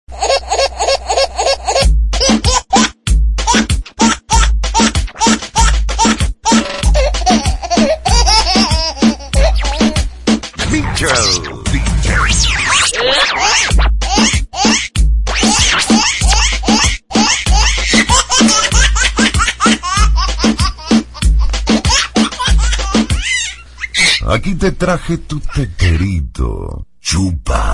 Tono de llamada Bebes riendo a carcajadas
Categoría Graciosos